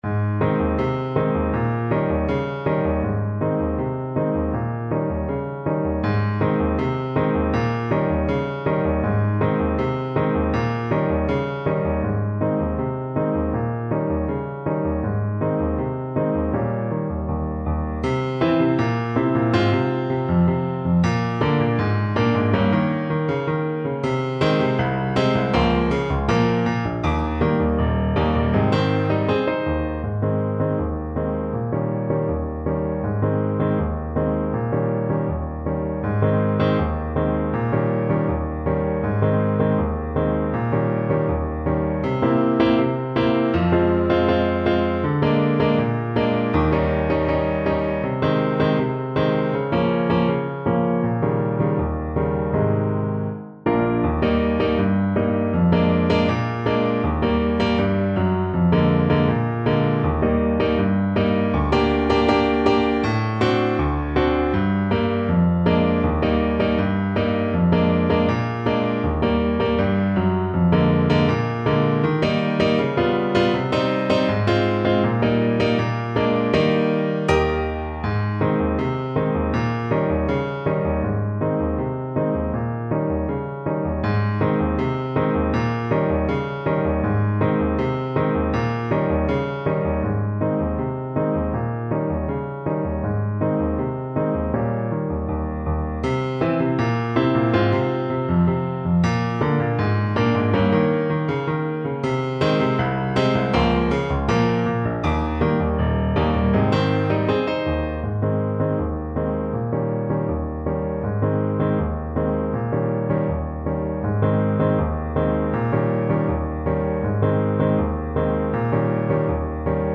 2/4 (View more 2/4 Music)
Not too fast = c.80
Brazilian Choro for Clarinet